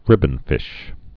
(rĭbən-fĭsh)